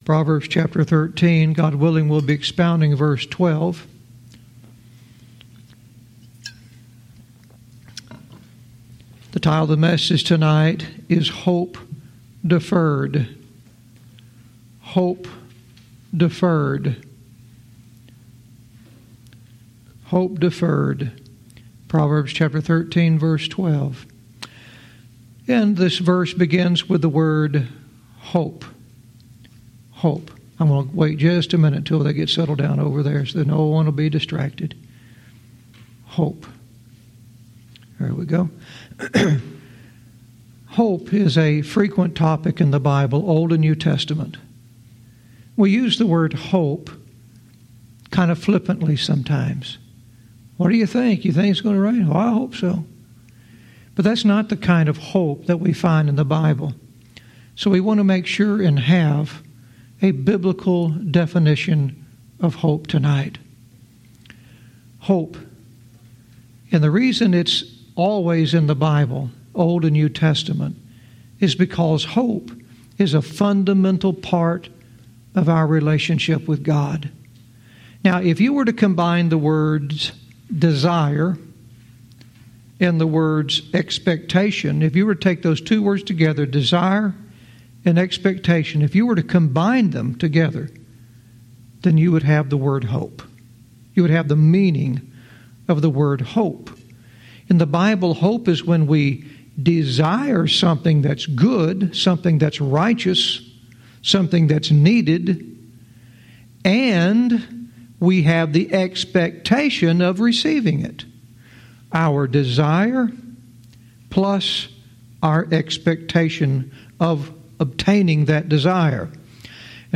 Verse by verse teaching - Proverbs 13:12 "Hope Deferred"